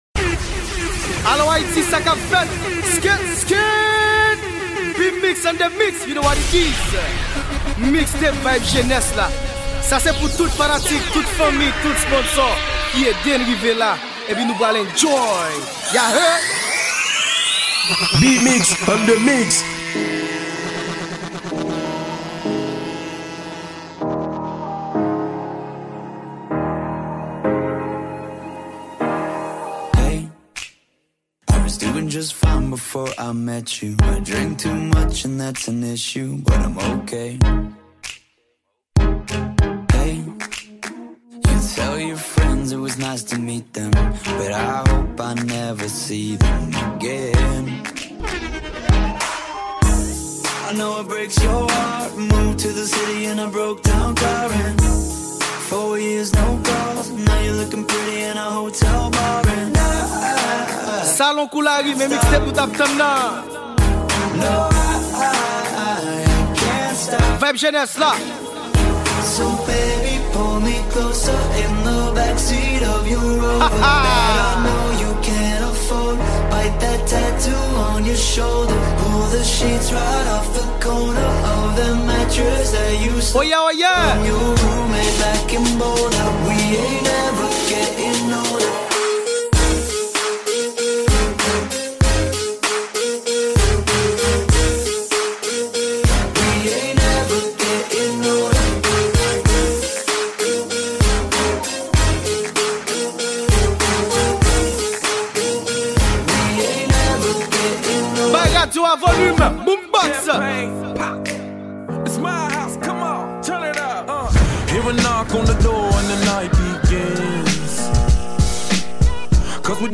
Genre: MIXES.